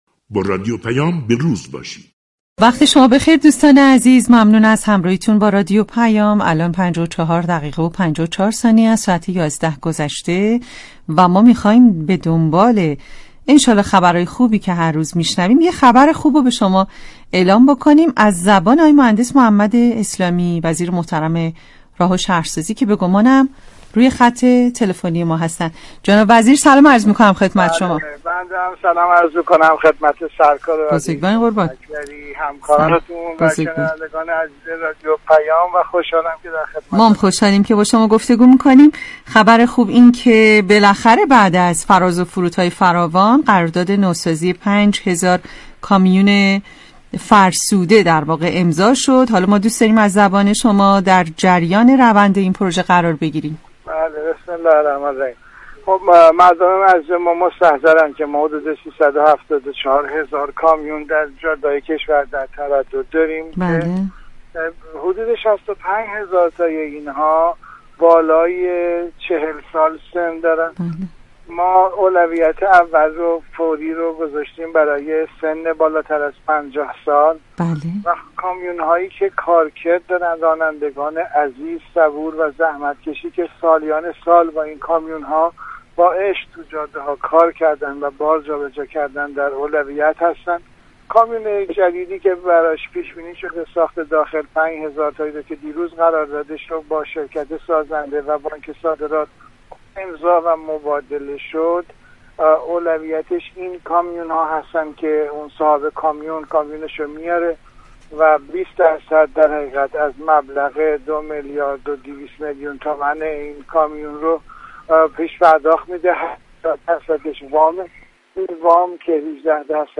مهندس محمد اسلامی وزیر محترم راه و شهرسازی در مصاحبه تلفنی با رادیو پیام گفت: در قالب این قرارداد پنج هزار دستگاه كامیون فرسوده از رده خارج و كامیون‌های نو جایگزین آنها می‌شود.